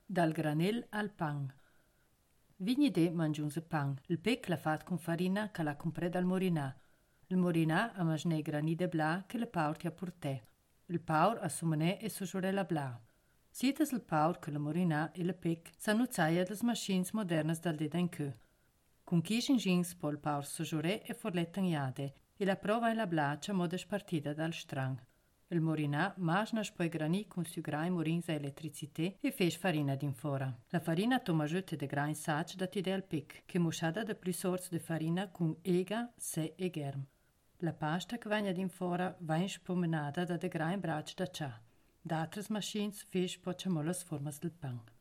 Ladino badiota